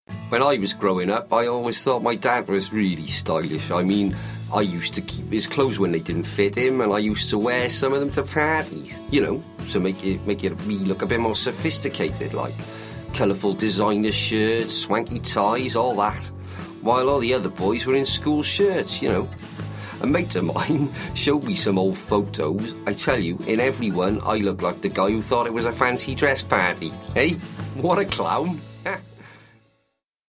Living TV Ident Welsh Funny
living-tv-ident-welsh-funny.mp3